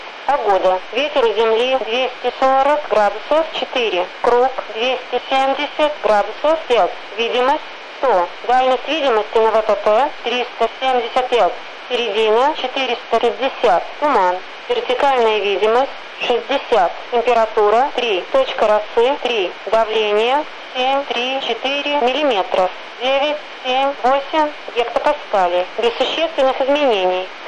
Внуково-АТИС